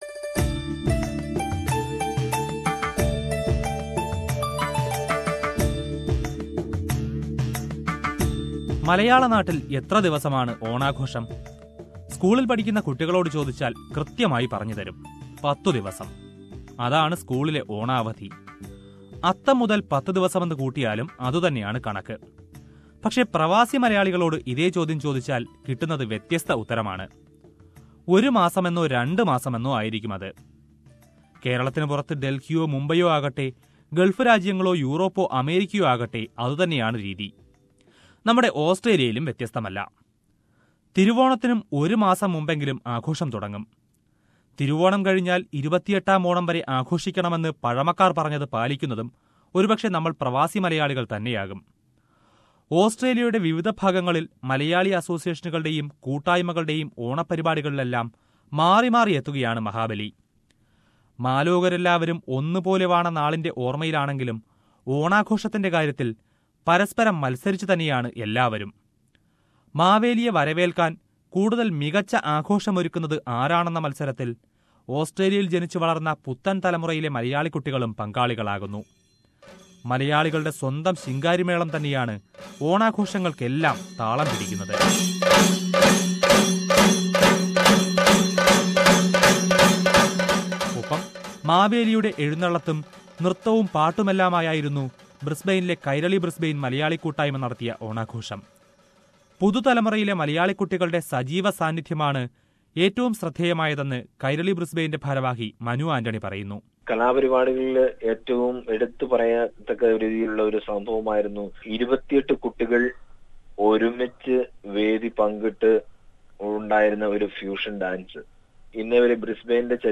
Australian Malayalees celebrated Onam in all major cities and in a number of regional suburbs. Let us listen to a report on the celebrations around the country.